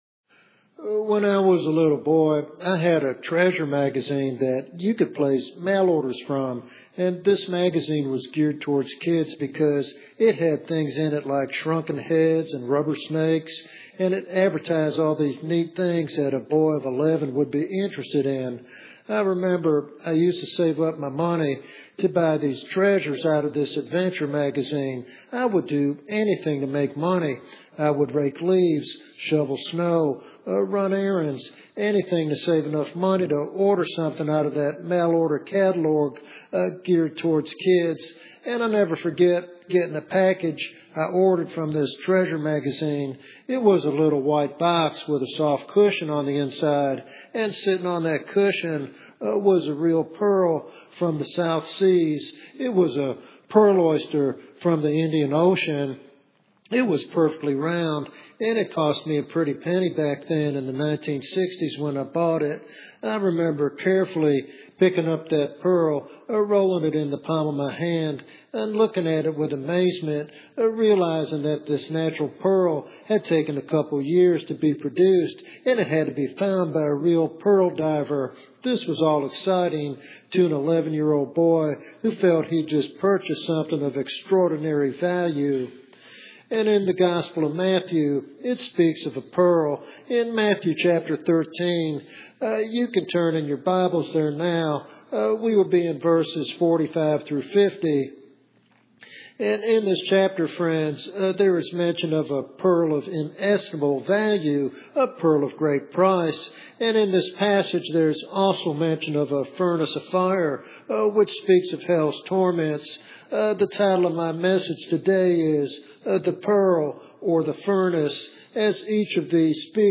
This topical sermon calls believers to a heartfelt choice between eternal treasure and eternal judgment.